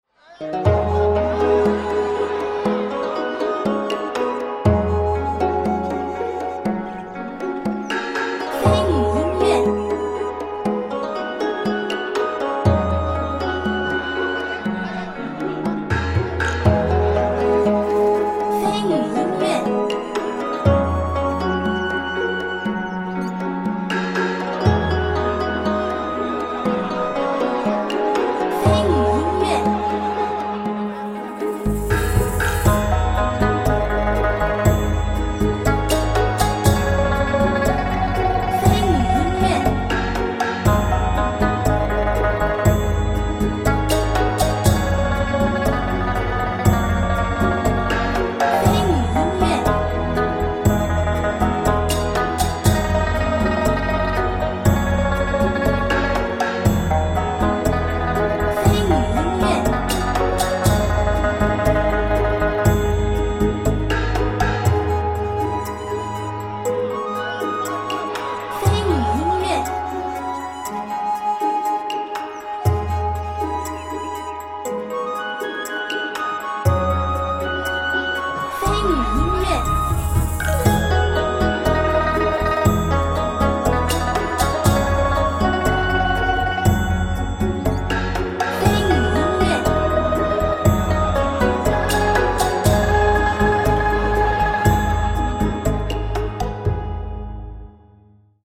中国风 管弦 平和 舒缓 优美